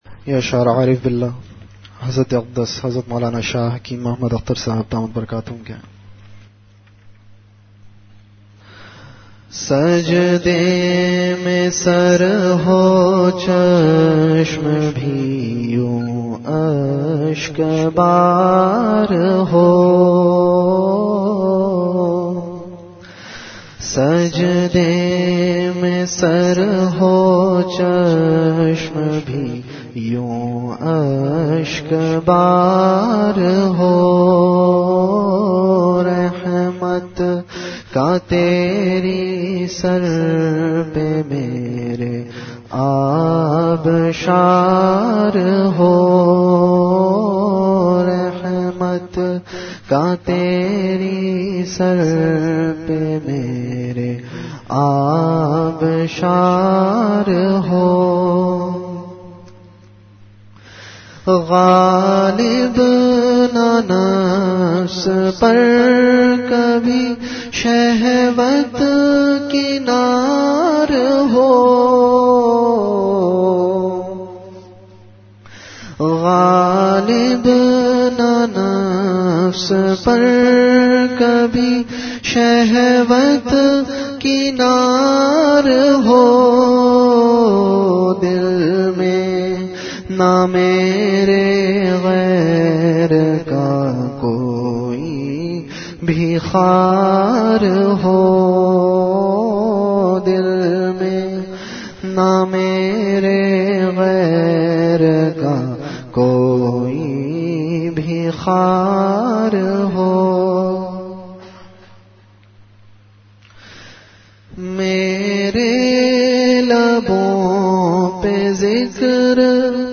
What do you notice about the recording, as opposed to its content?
Venue Home Event / Time After Isha Prayer